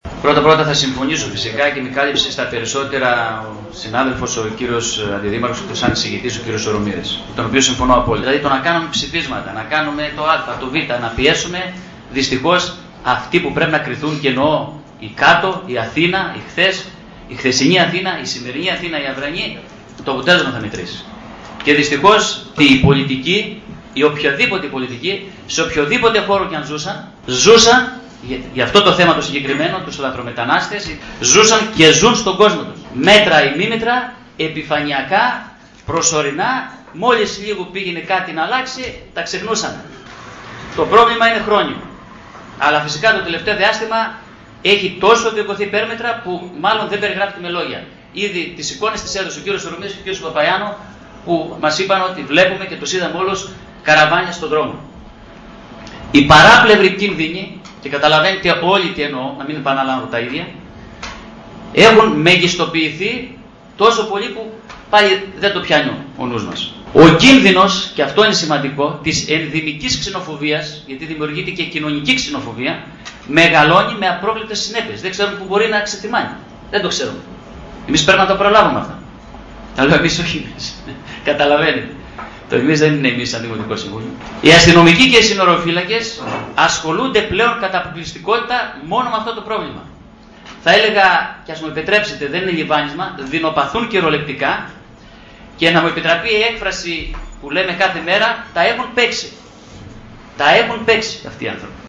Κώστας Λύτρας, δημ. σύμβ., για το θέμα της λαθρομετανάστευσης – Δημ.Συμβ. Ορ/δας 14.09.2010